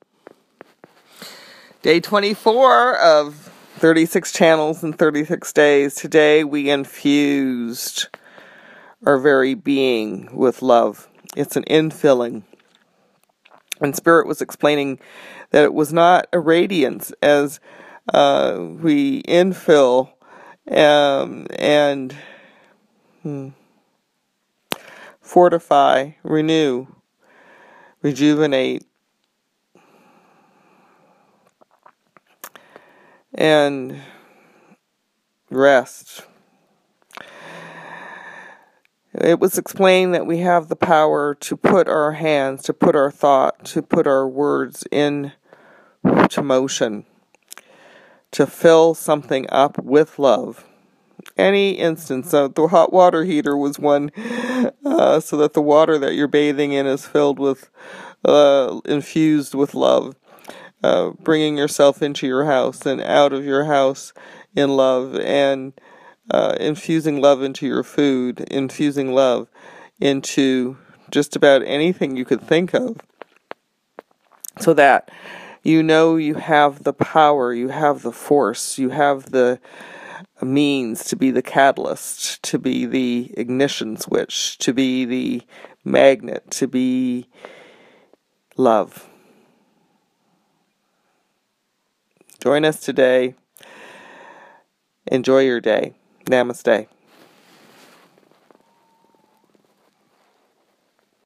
Each Channel is RECORDED in the morning and then POSTED on the 36 CHANNELS IN 36 DAYS web page later in the day.
HERE IS TODAY’S INTRODUCTION!